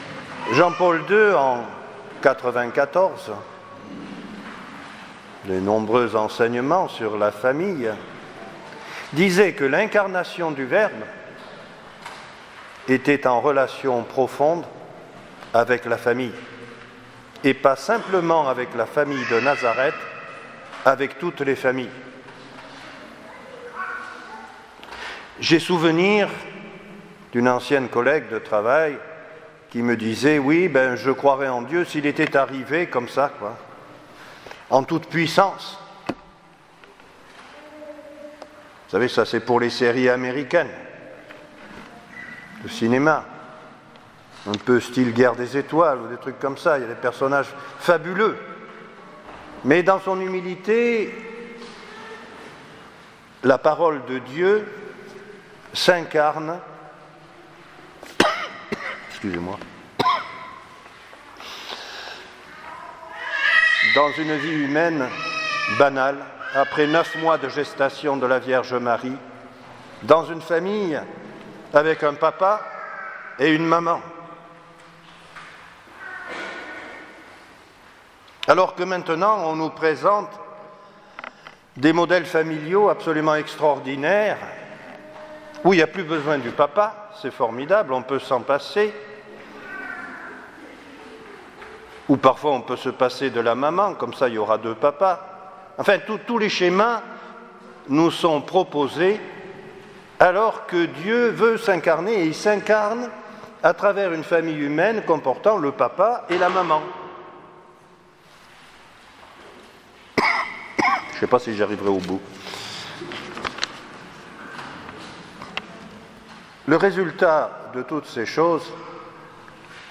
Homélie du dimanche 31 décembre 2017 | Les Amis du Broussey